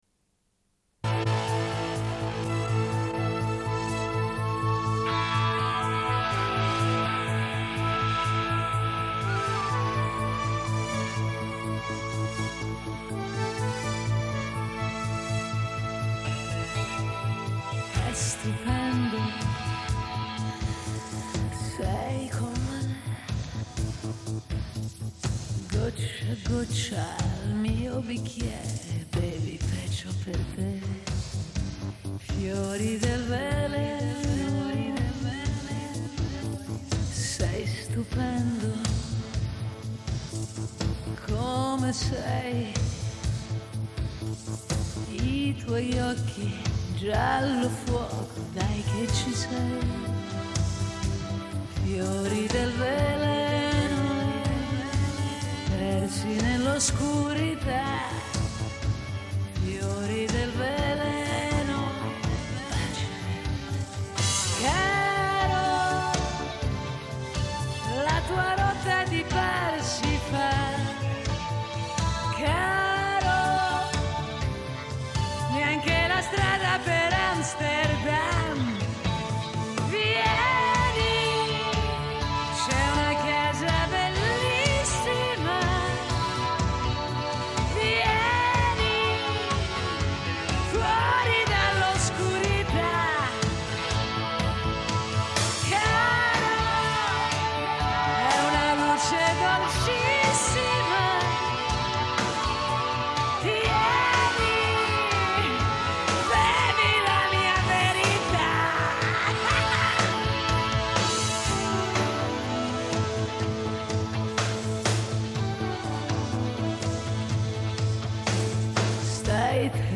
Une cassette audio, face B